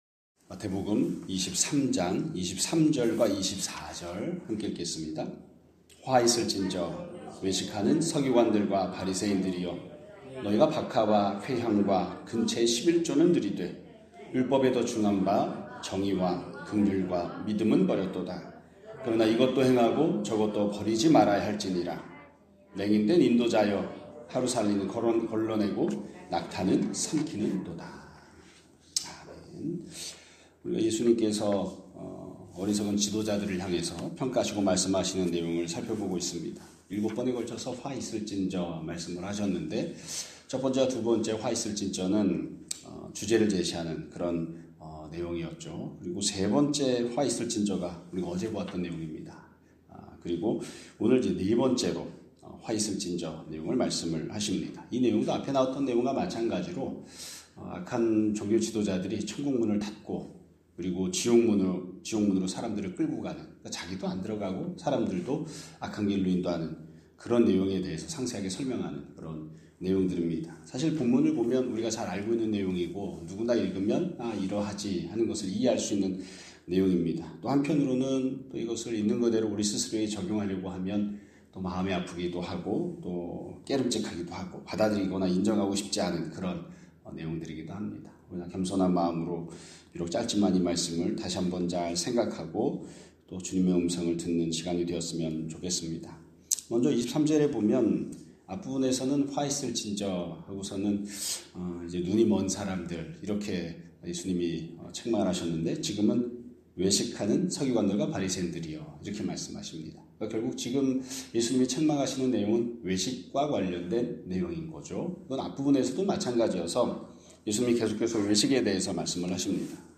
2026년 2월 25일 (수요일) <아침예배> 설교입니다.